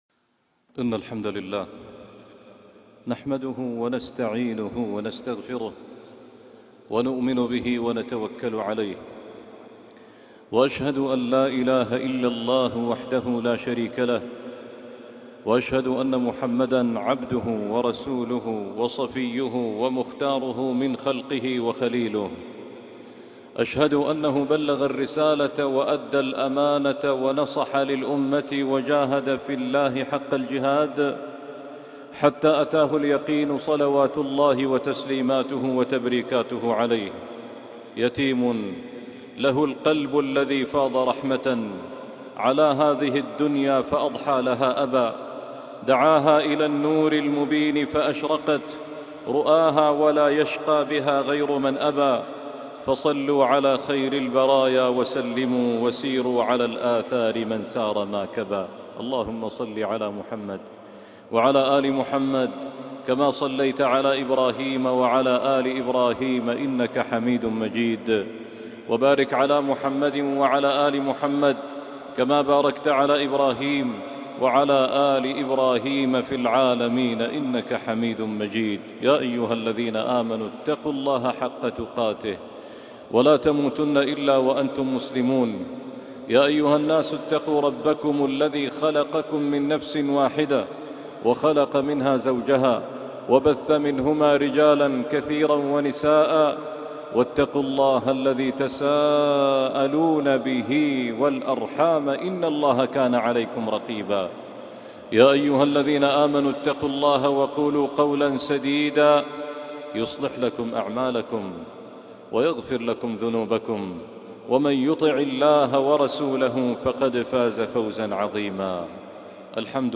القرآن والجناب النبوي - خطبة وصلاة الجمعة